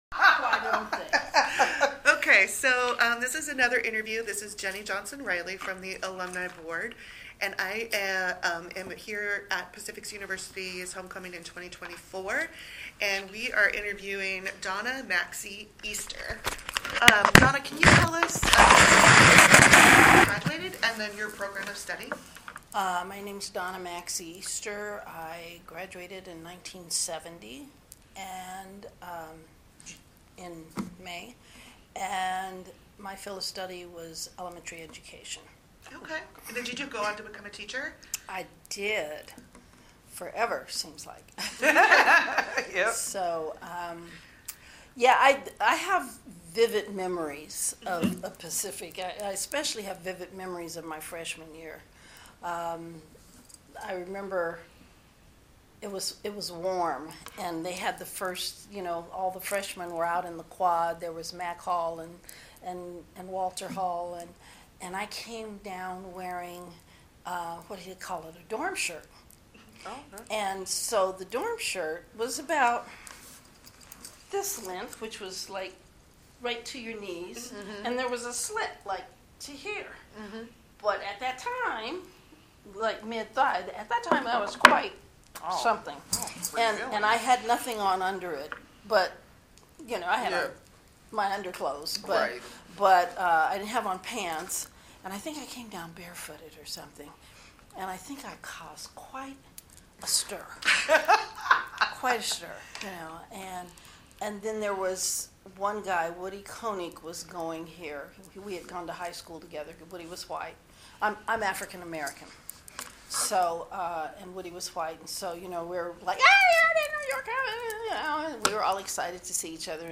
oral history recording
This is one of a group of recordings made during a reunion in October, 2024.